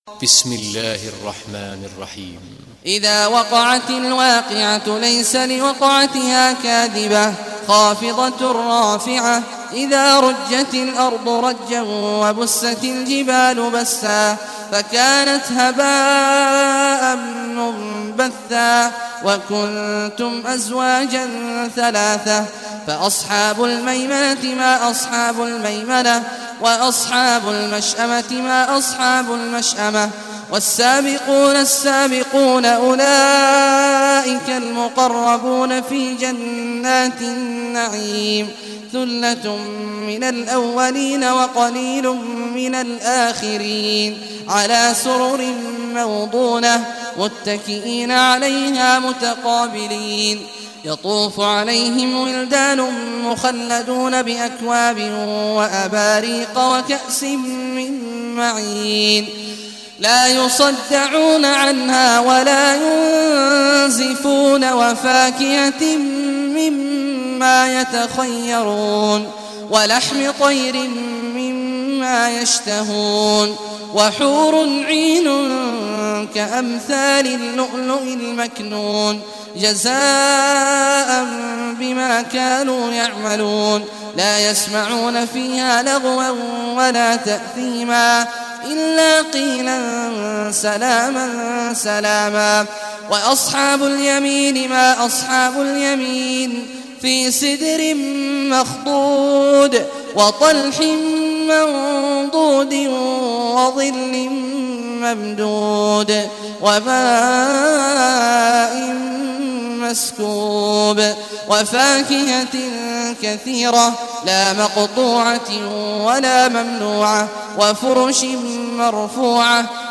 Surah Waqiah Recitation by Sheikh Abdullah Juhany